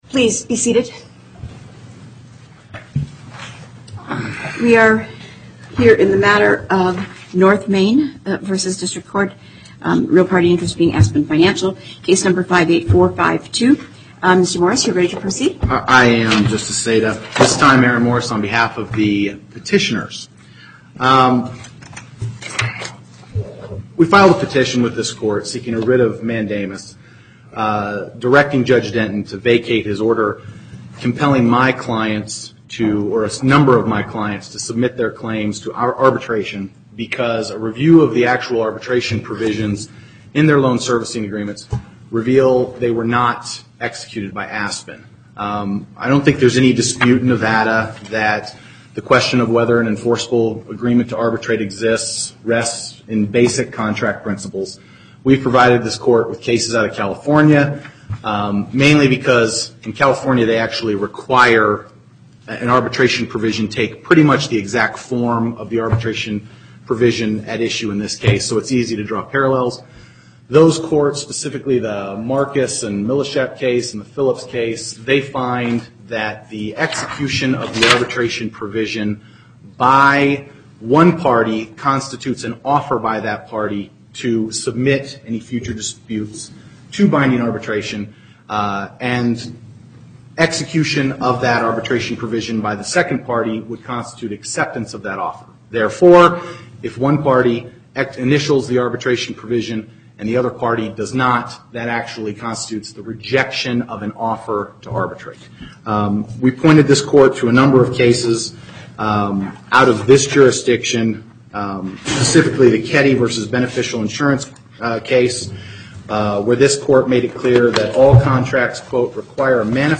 Location: Carson City En Banc Court, Chief Justice Saitta Presiding